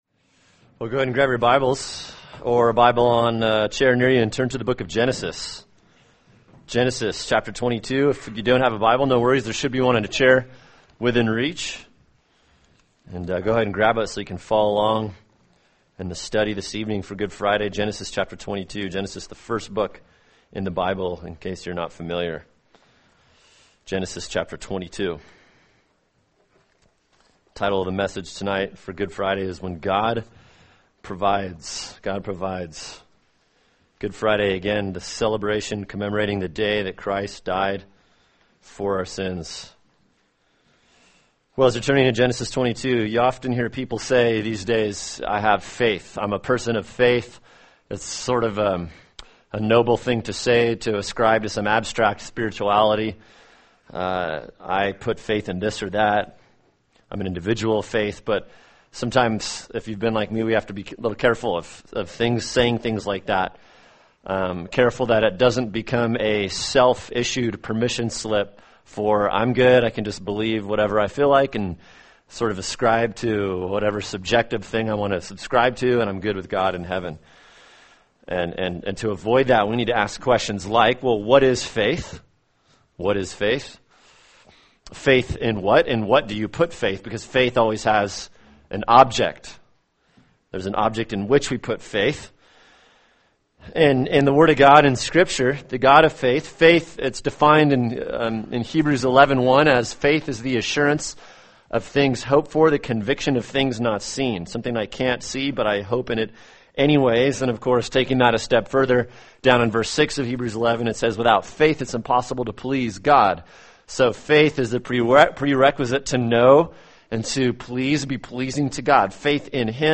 [sermon] Genesis 22 “When God Provides” (Good Friday) | Cornerstone Church - Jackson Hole